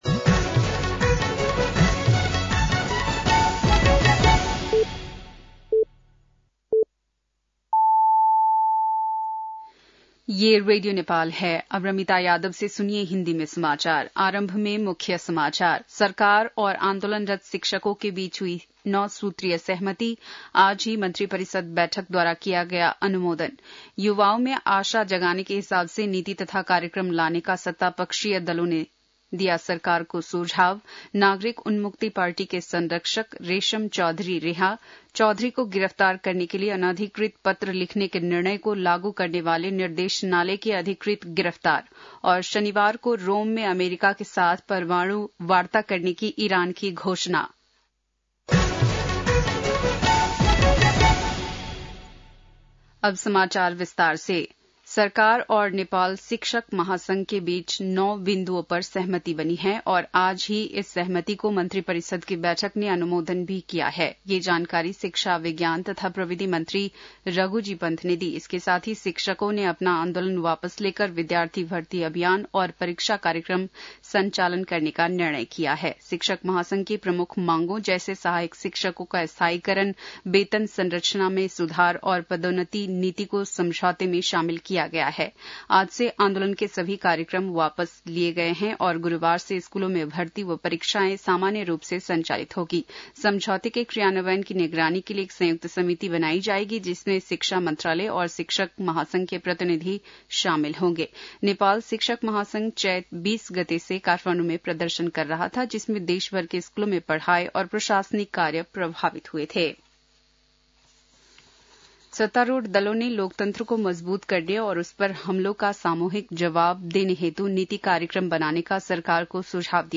बेलुकी १० बजेको हिन्दी समाचार : १७ वैशाख , २०८२